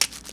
HIT.3.NEPT.wav